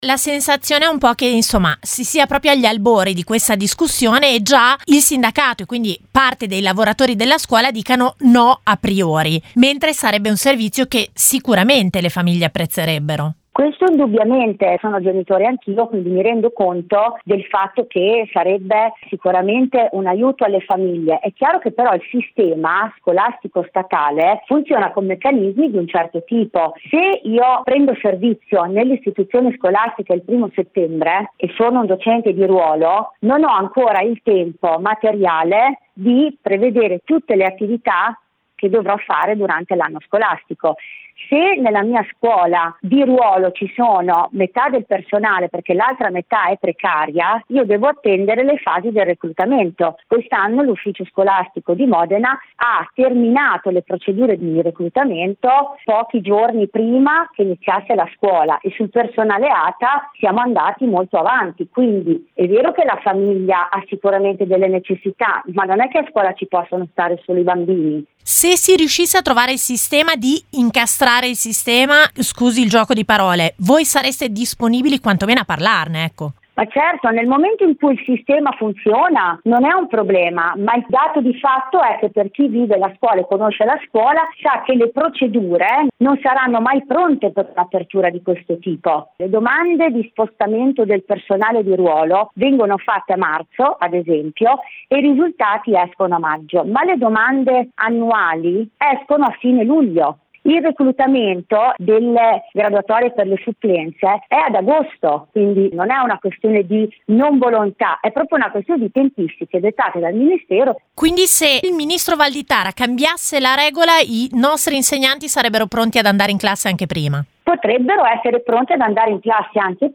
intervistata